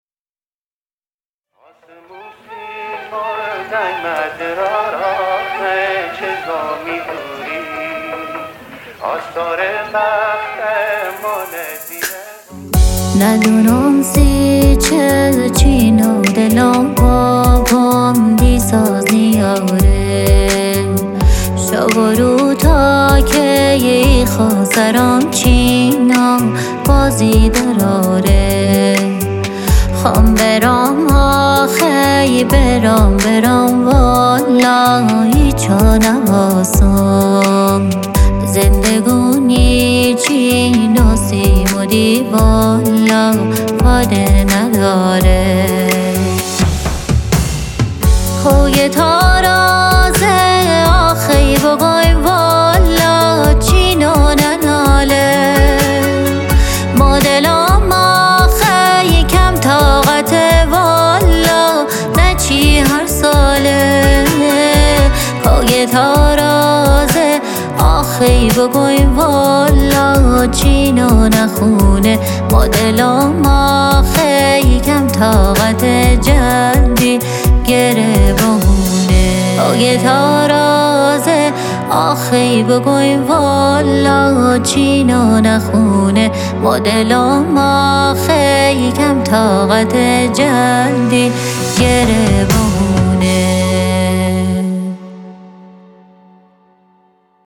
ترانه سرا و خواننده ایرانی
پاپ